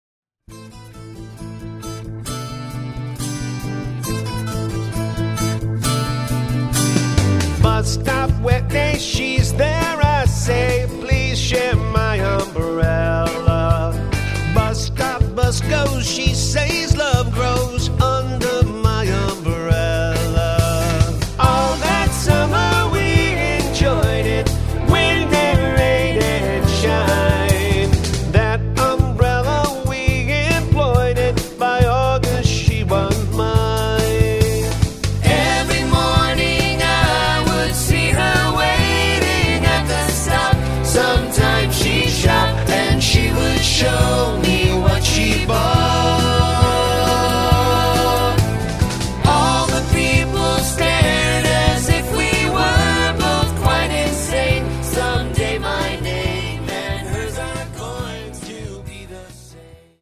Lead Vocals
Cover